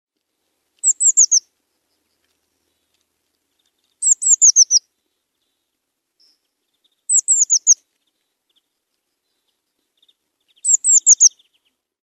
Kuuntele sinitiaisen kutsuääntä